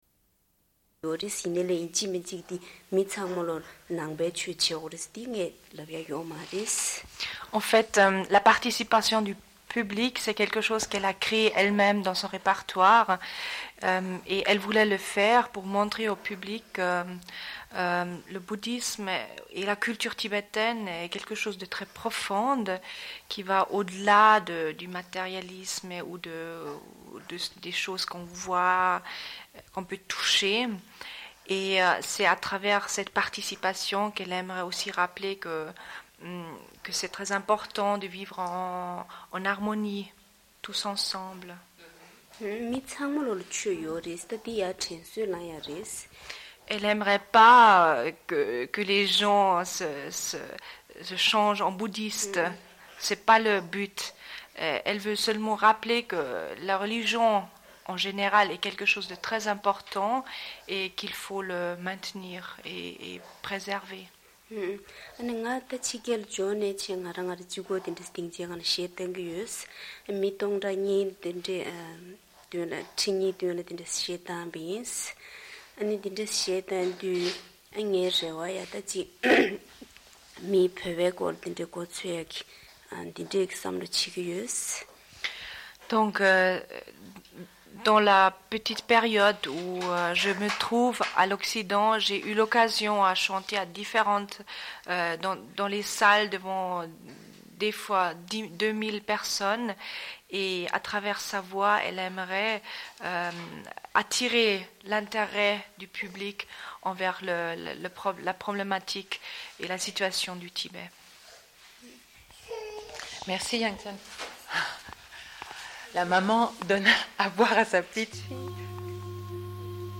Une cassette audio, face B
Radio Enregistrement sonore